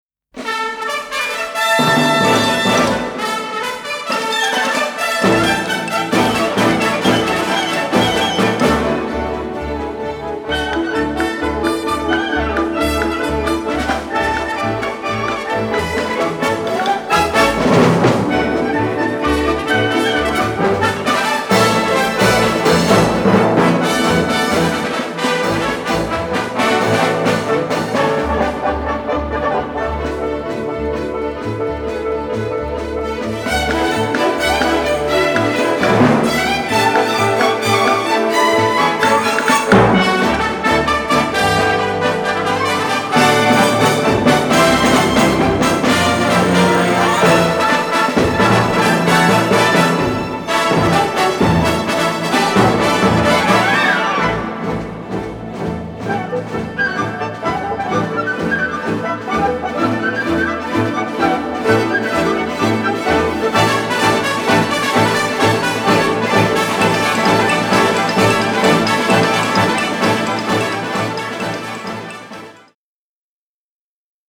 striking western score
unedited 2-track stereo masters